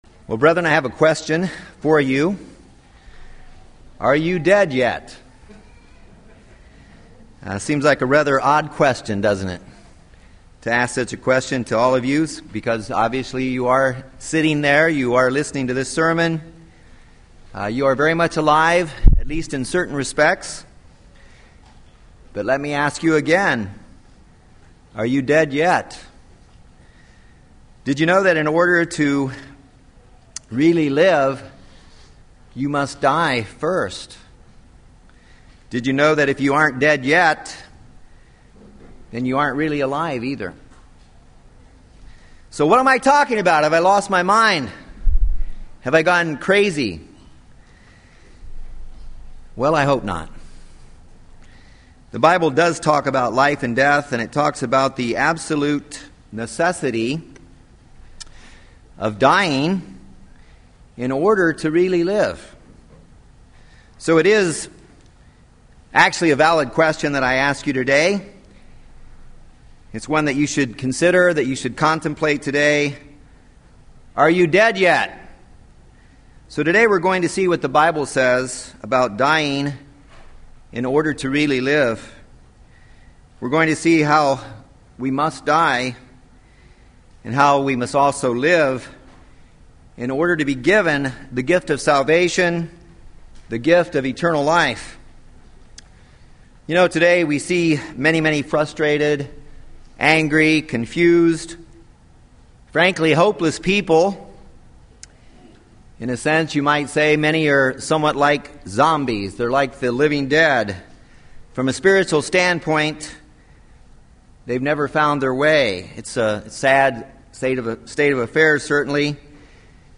And what does it mean to be truly alive in Christ? These questions are answered in this sermon.